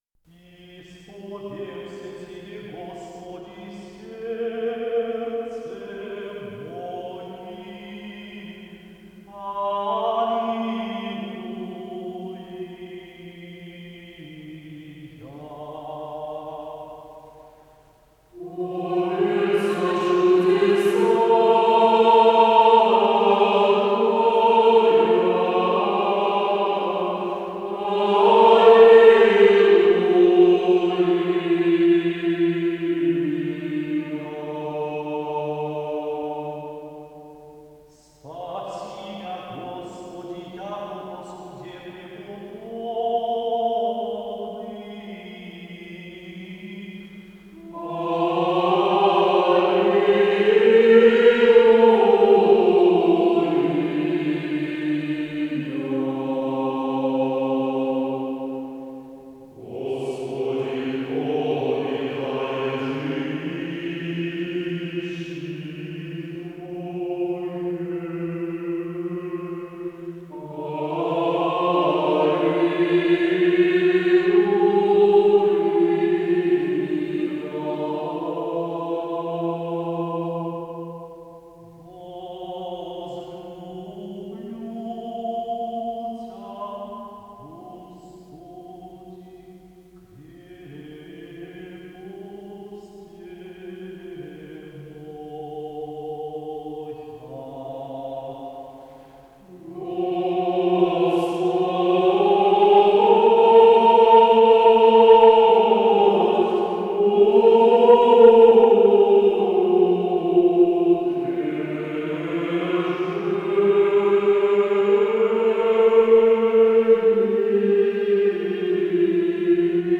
ИсполнителиКамерный хор
тенор
Скорость ленты38 см/с
ВариантДубль моно